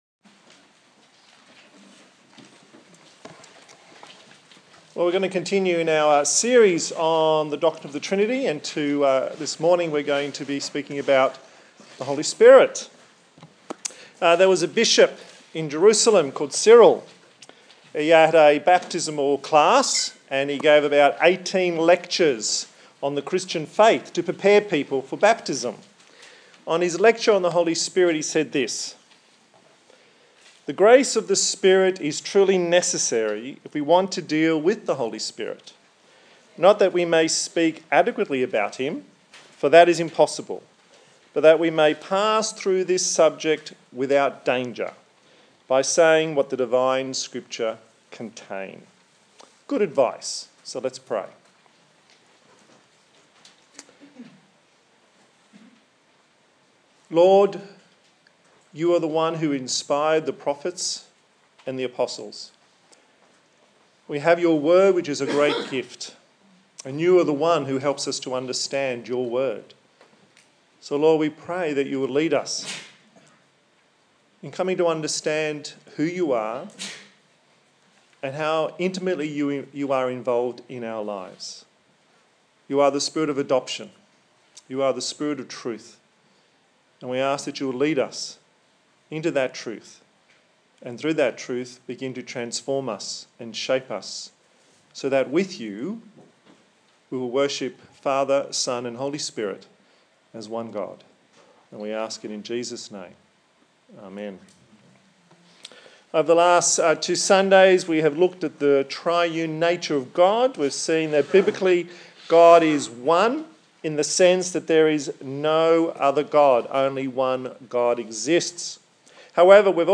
The third sermon in the Trinity series of sermons about God in three persons. The Holy Spirit is God and points us to Jesus. He is the Spirit of God with us, in us and for us.
Isaiah 44:1-8 Service Type: Sunday Morning The third sermon in the Trinity series of sermons about God in three persons.